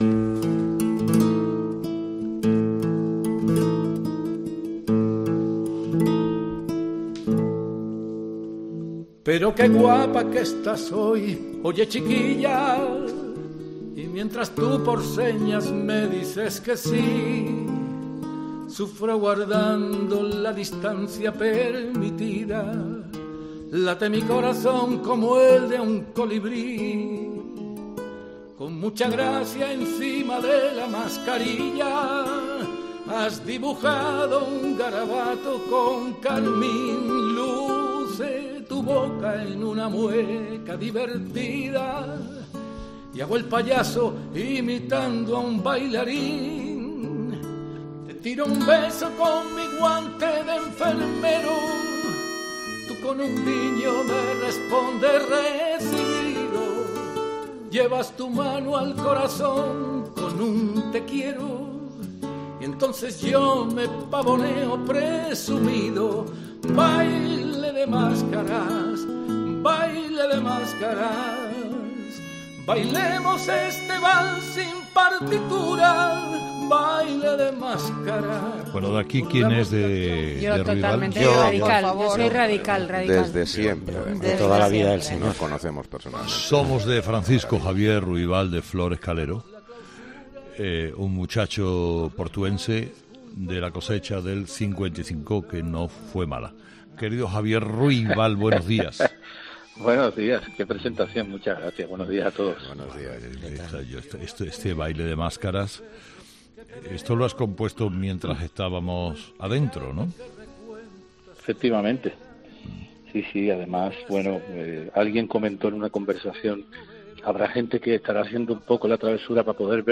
El cantautor que acaba de escribir un libro de poemas ha dejado su "especial forma" de cantar a la vida en "Herrera en COPE"